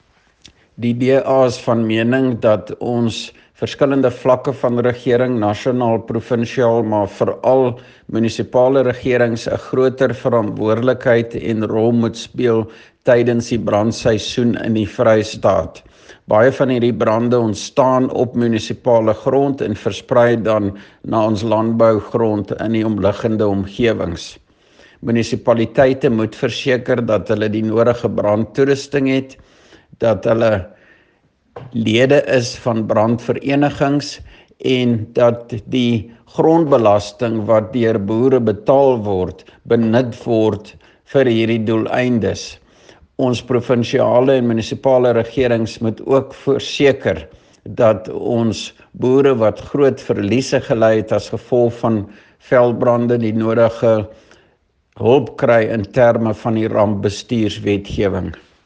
Afrikaans soundbites by Dr Roy Jankielsohn MPL.